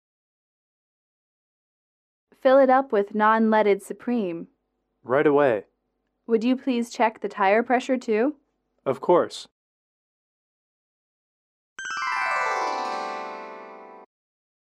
英语口语情景短对话06-1：加油站